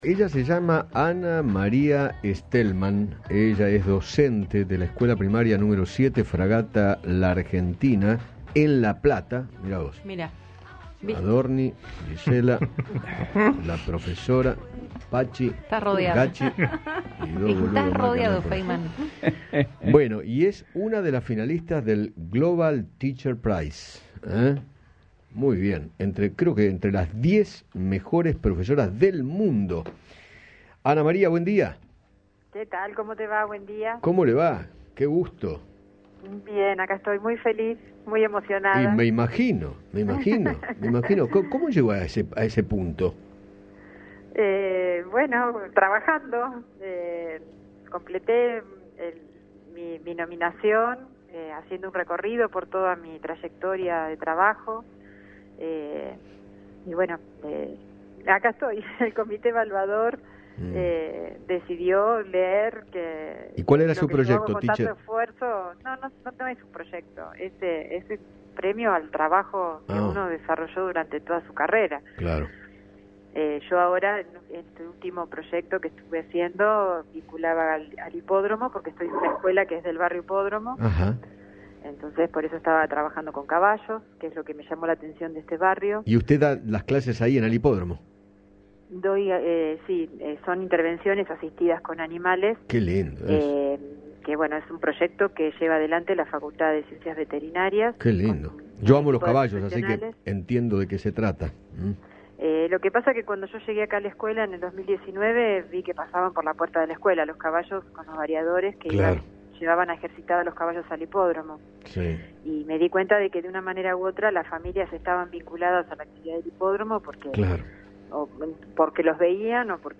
Eduardo Feinmann conversó con